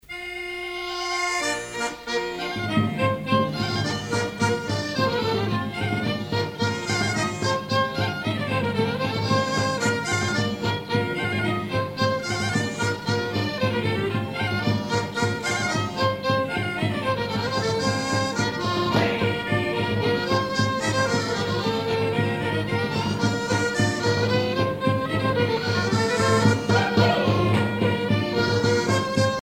Usage d'après l'analyste gestuel : danse ;
Catégorie Pièce musicale éditée